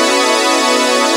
Synth Lick 50-02.wav